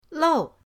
lou4.mp3